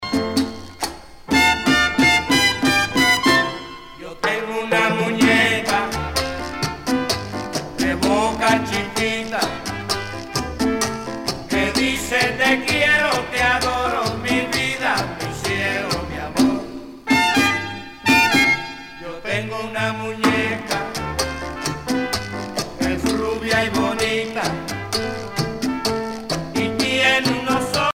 danse : cha cha cha